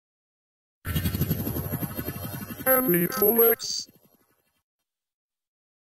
aniplex-sfx.mp3